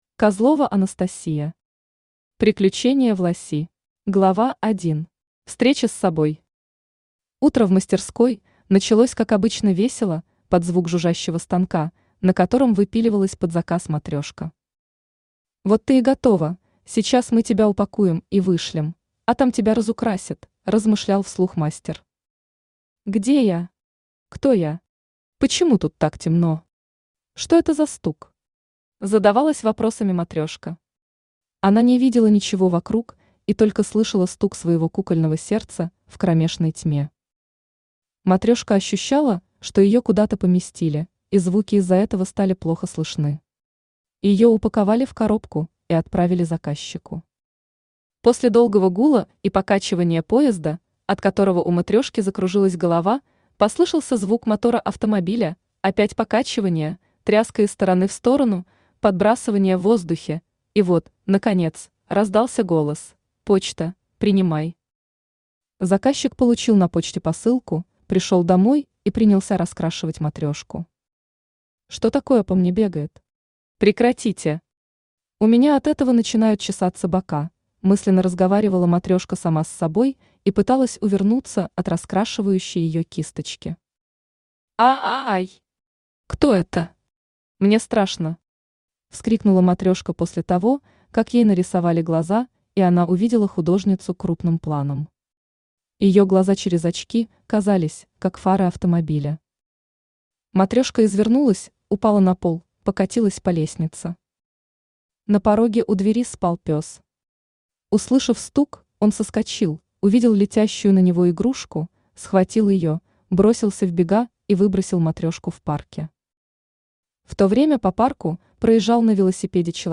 Аудиокнига Приключения Власи | Библиотека аудиокниг
Aудиокнига Приключения Власи Автор Козлова Анастасия Читает аудиокнигу Авточтец ЛитРес.